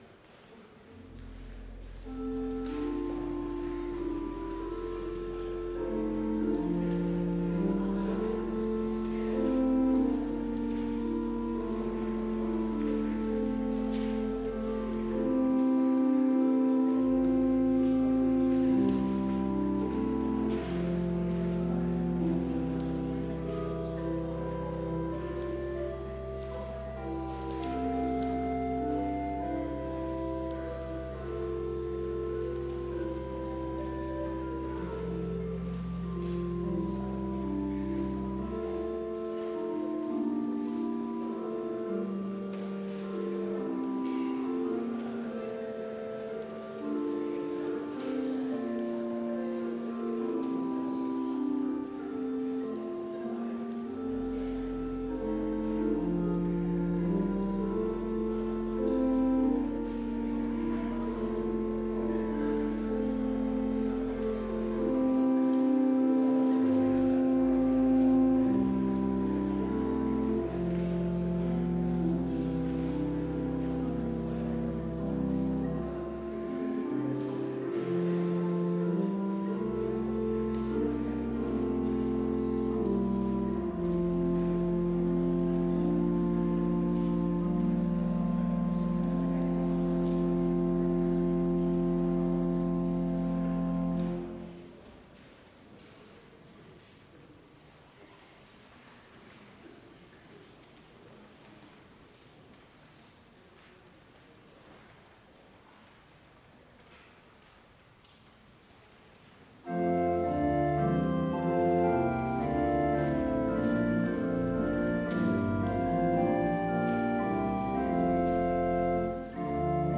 Complete service audio for Chapel - March 31, 2022